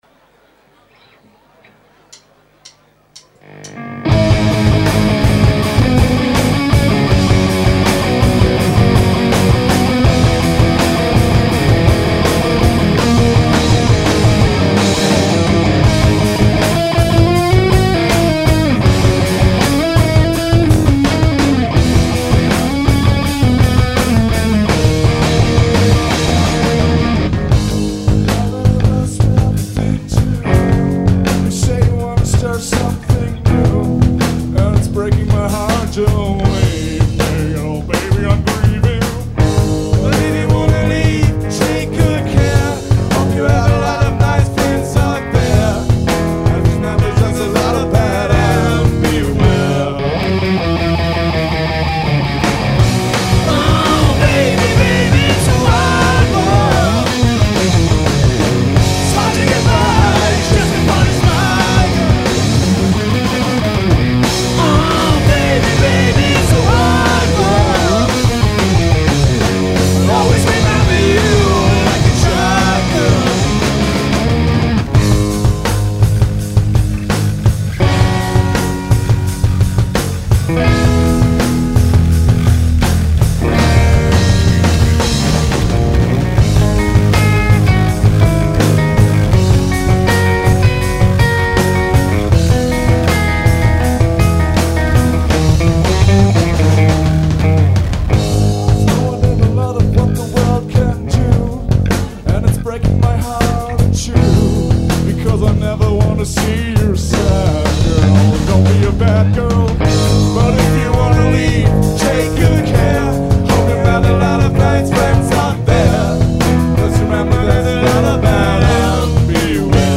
Cover-Songs (live):